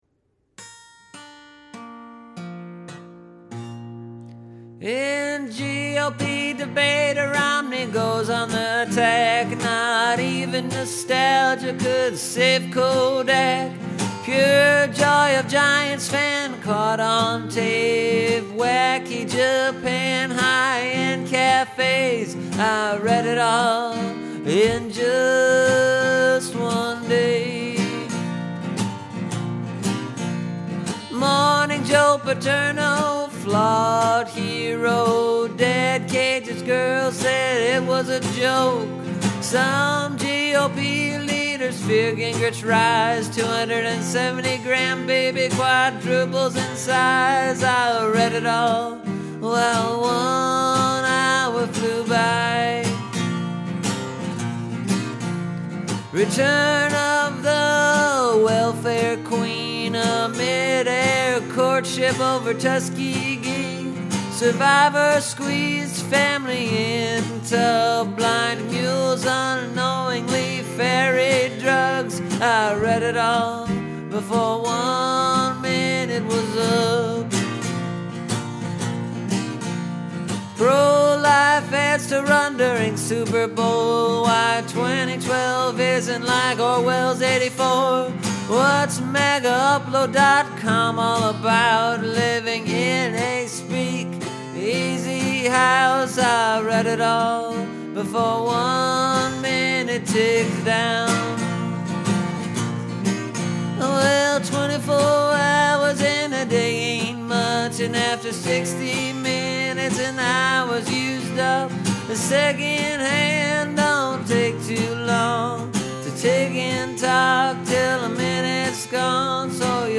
Share this: Tweet Share on Tumblr Related blues folk folk music home recording music recording song songwriting